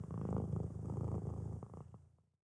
Hub Cat Sound Effect
hub-cat-5.mp3